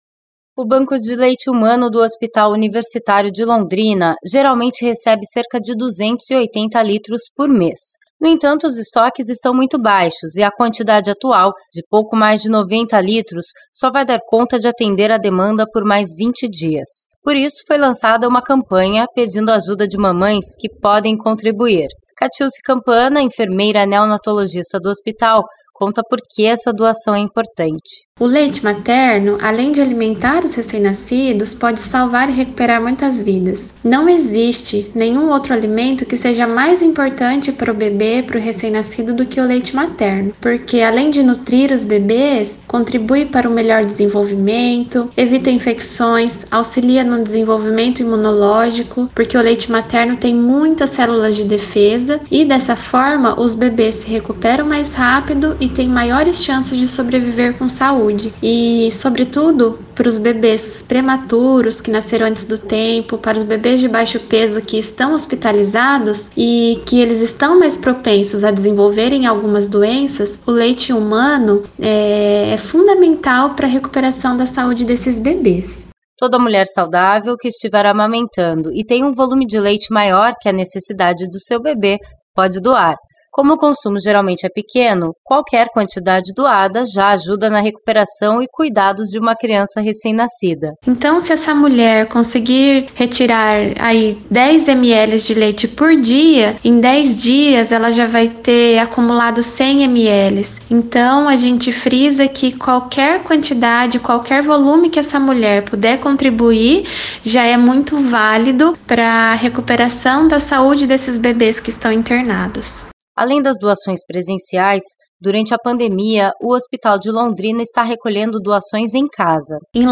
A baixa nos estoques fez o Hospital Universitário de Londrina iniciar uma campanha e coletar doações de leite materno em casa. Saiba mais na reportagem da série Vale Muito.